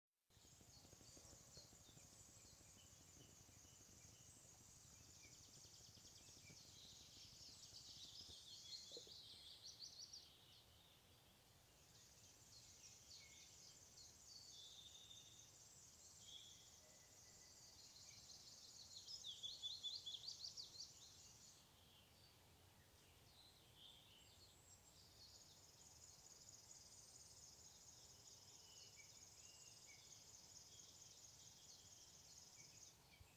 River Warbler, Locustella fluviatilis
StatusSinging male in breeding season